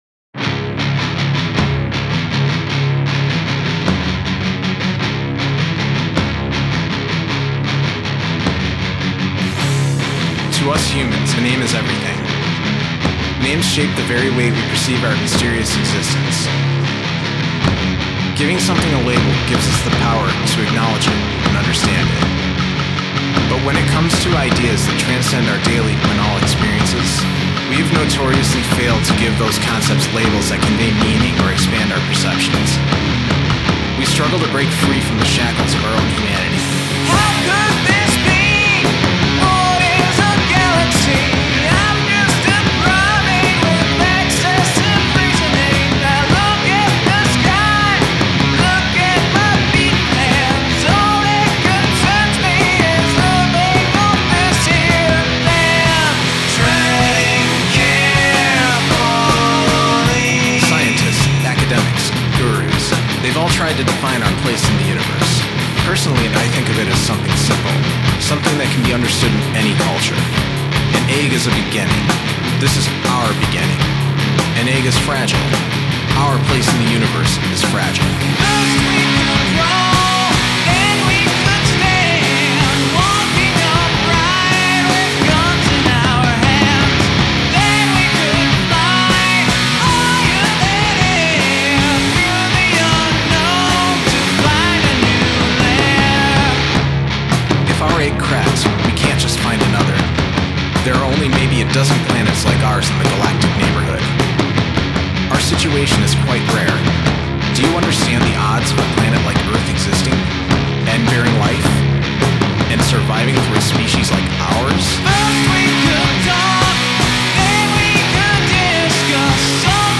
Guest spoken word